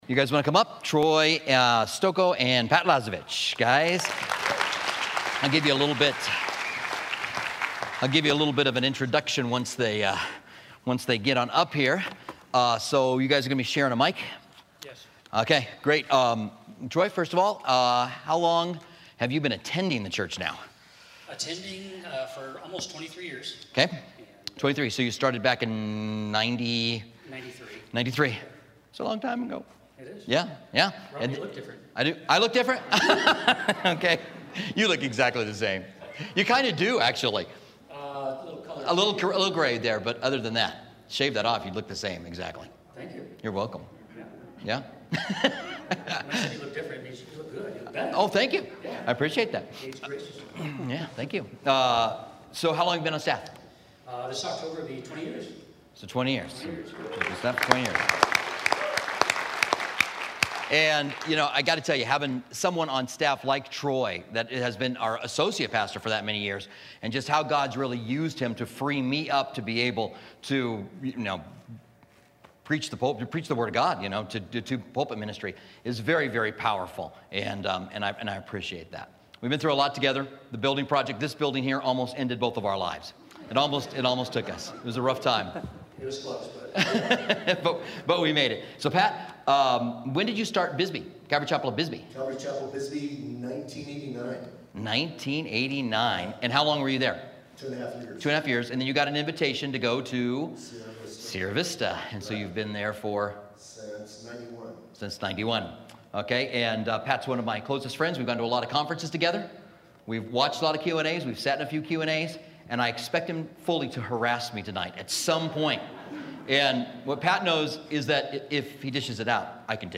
2016 · Multiple Listen to a pannel of senior pasors give their answers for questions submitted to the Inquire Q&A series.